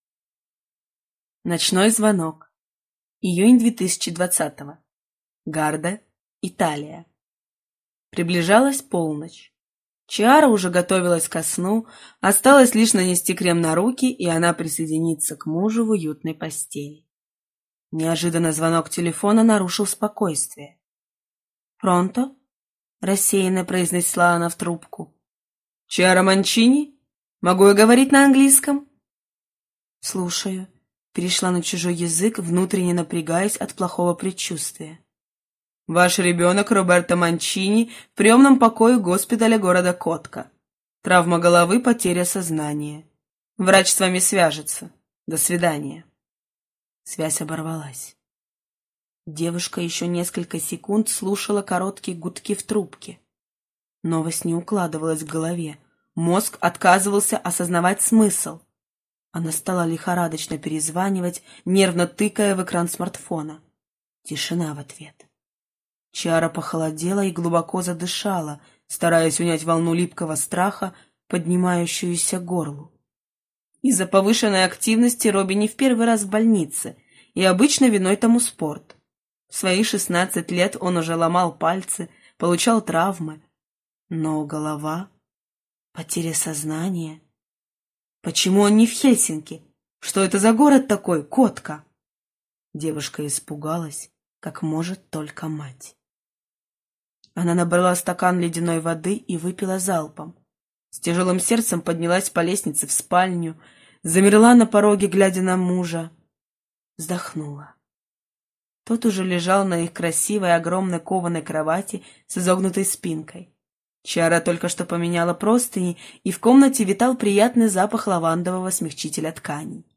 Аудиокнига Изоляция | Библиотека аудиокниг